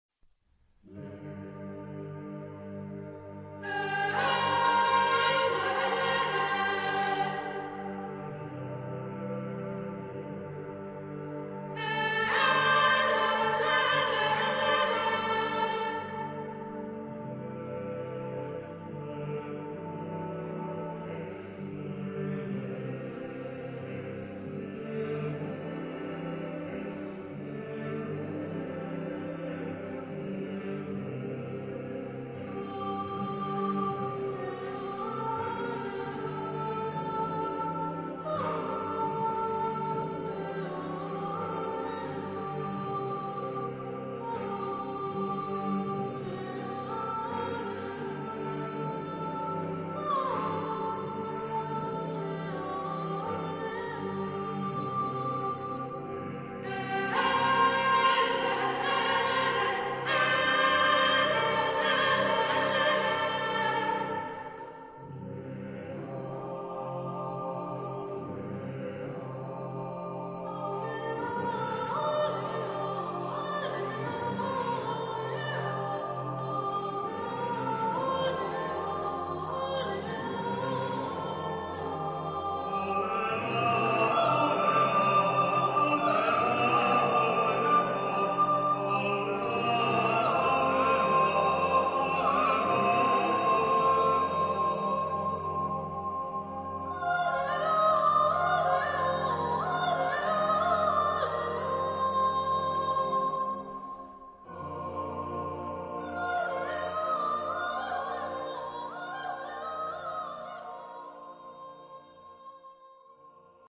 SAATB (5 voices mixed).